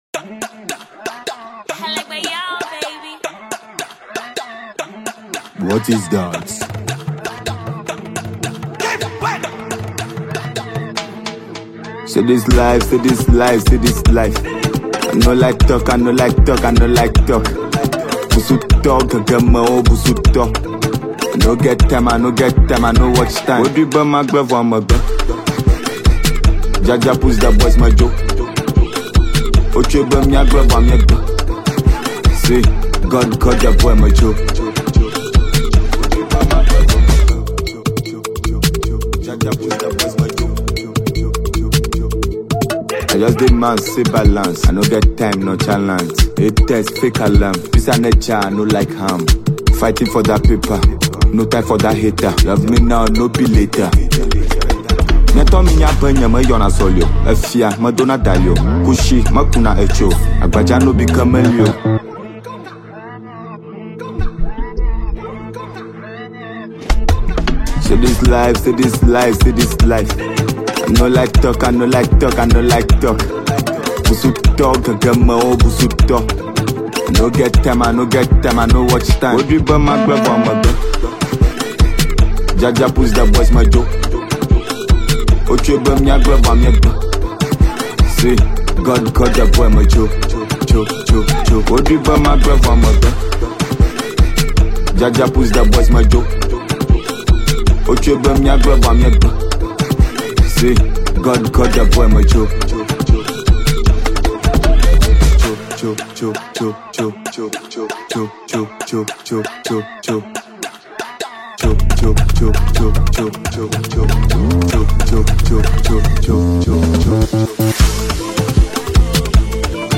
Ghanaian rapper and songwriter